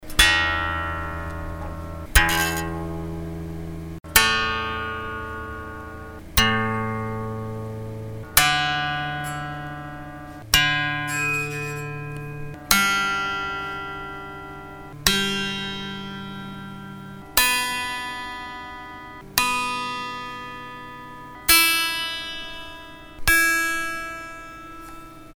Allerding testete ich allein Zuhause, nicht in einer Band.
Ich machte den Vergleich nochmal, ein wenig schnarrt es noch, das angestrengte "Setup" erfolgte danach.
Bei der ersten hätte ich ne Strat oder Tele vermutet, so hell klingt die. 2 Nachtrag: Ohne jetzt eine erschöpfend unsägliche Diskussion wieder beleben zu wollen.